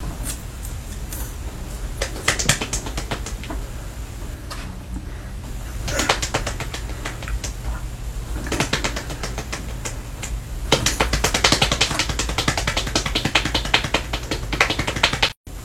Sound Buttons: Sound Buttons View : Lawnmower
lawnmower.mp3